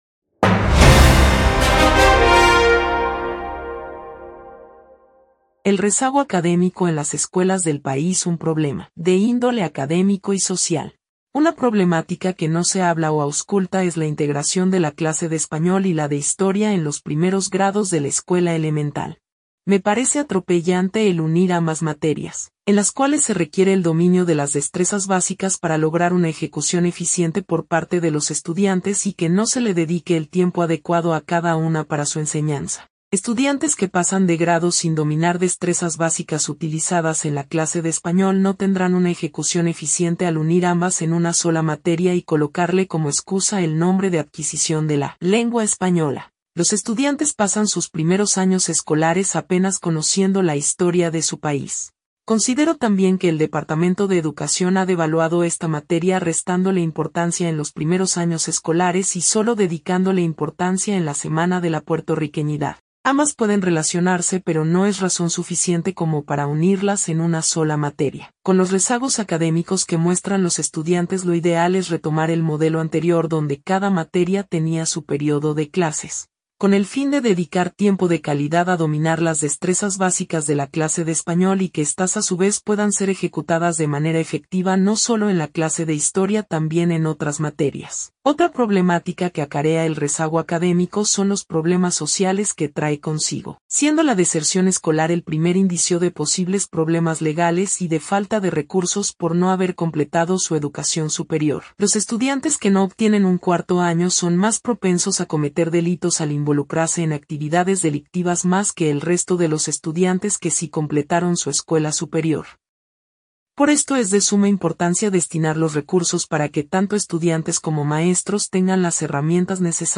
En el podcast, una maestra explica el rezago academico y cuestiona la union de la materia de Español y Estudios Sociales como una sola materia.